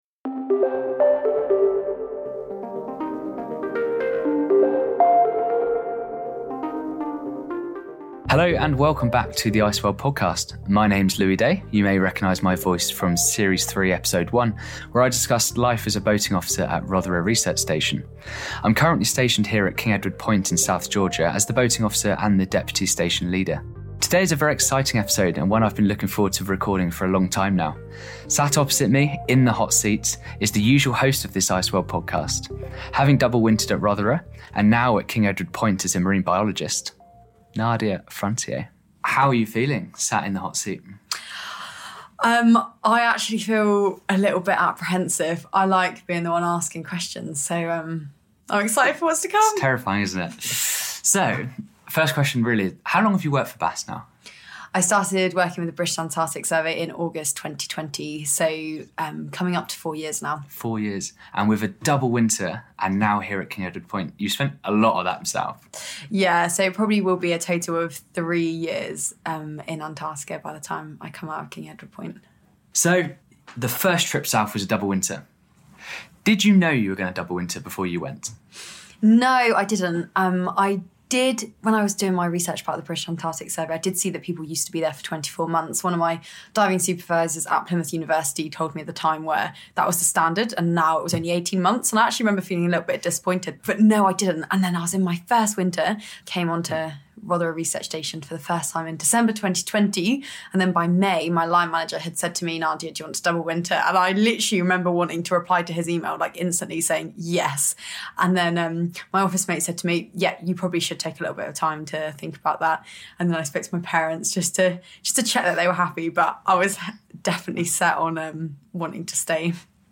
Interviewed at King Edward Point